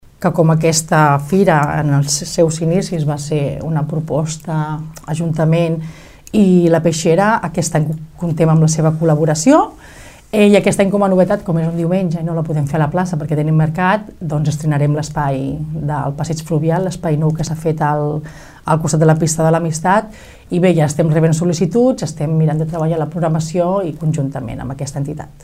Nàdia Cantero és regidora de promoció econòmica.